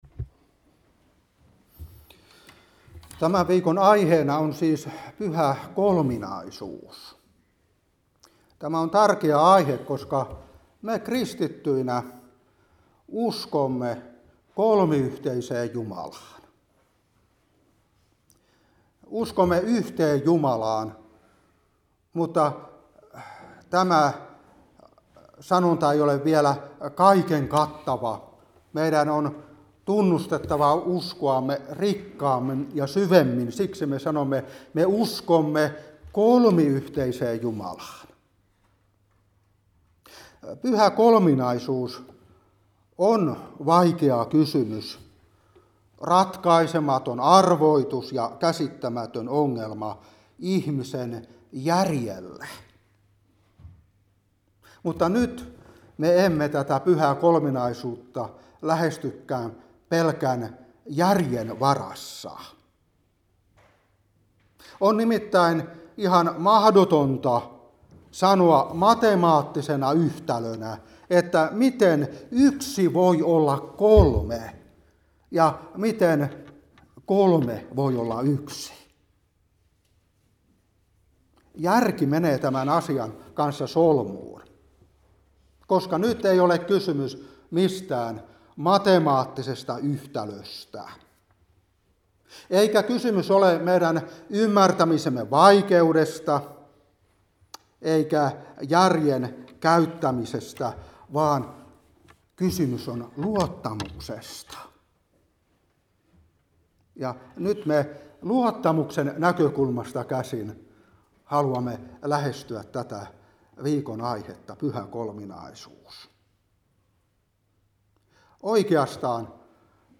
Opetuspuhe 2022-6. Joh.16:13-15. Joh.11:25-26. Joh.14:1-6.